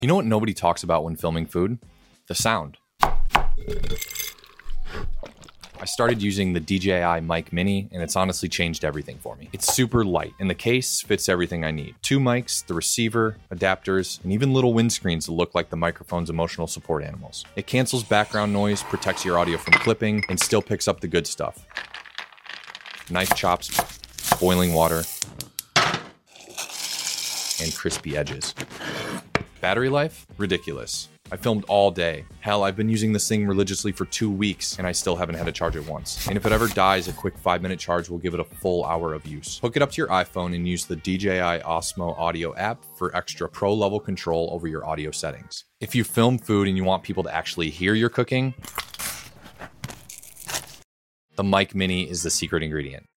Good noise cancellation. Zero clipping. Even picks up the tiny sounds. Every sizzle, every crunch, every drip.